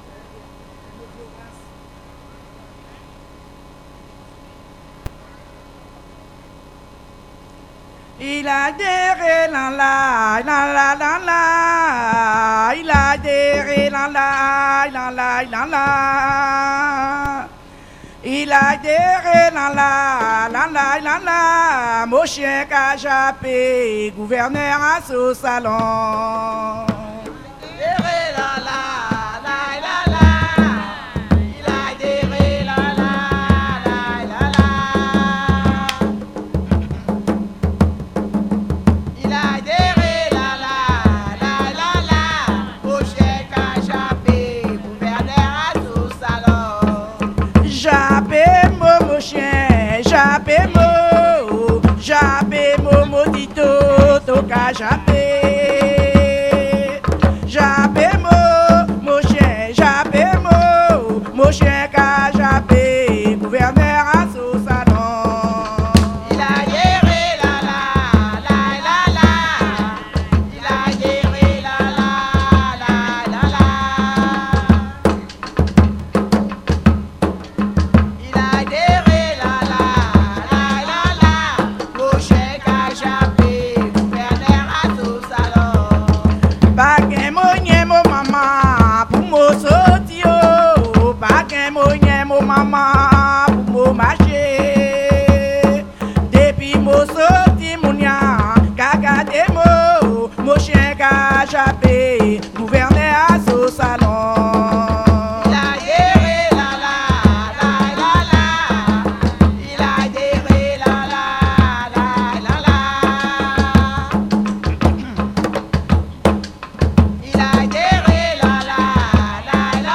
Localisation Saint-Georges-de-l'Oyapoc
danse : grajévals (créole) ; danse : bélya (créole)
Pièce musicale inédite